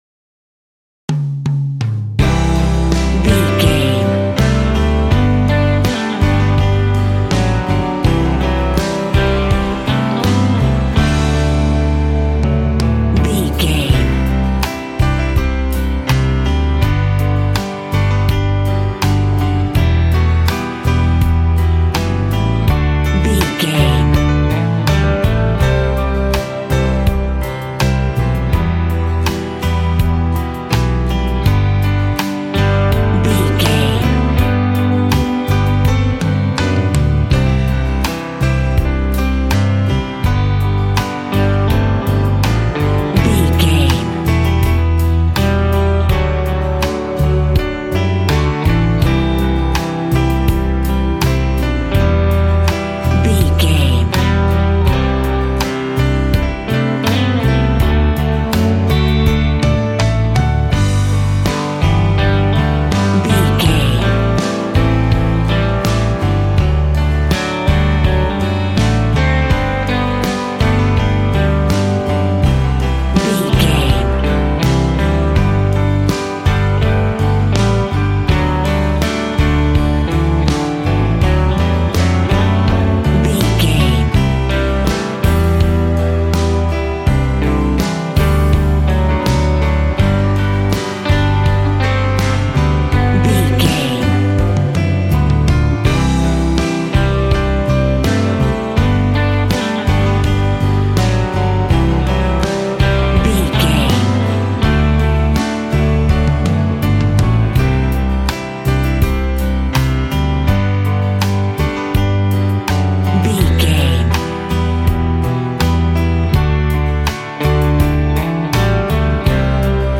Ionian/Major
romantic
sweet
happy
acoustic guitar
bass guitar
drums